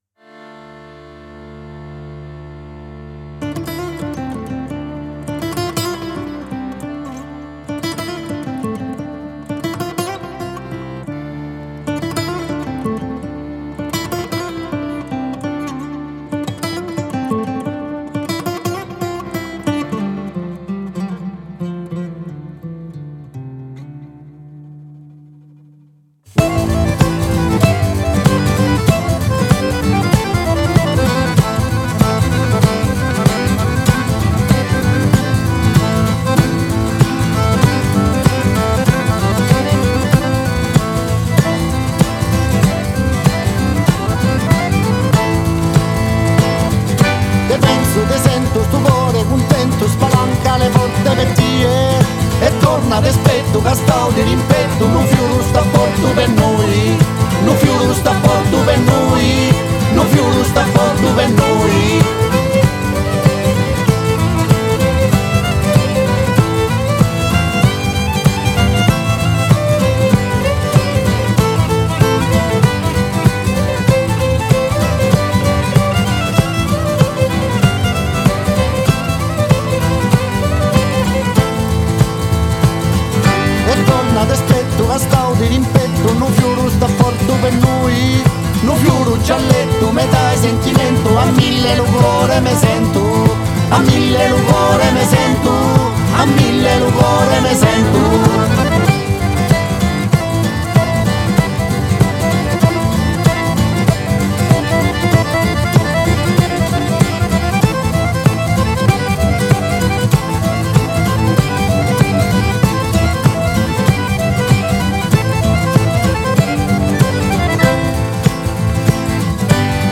REGISTRATO PRESSO BPM Studio
GENERE Folk / Pizzica Salentina /World Music
musica dal ritmo forte, quasi violento
tamburello
fisarmonica
violino
chitarra
oboe